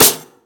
CardiakSnare3.wav